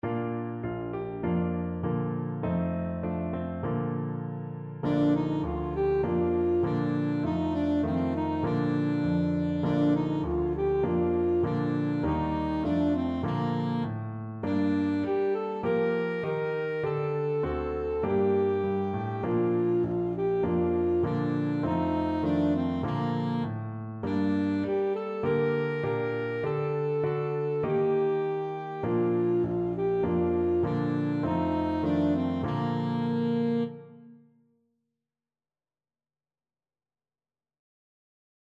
Alto Saxophone
4/4 (View more 4/4 Music)
Bb4-Bb5
Traditional (View more Traditional Saxophone Music)
Israeli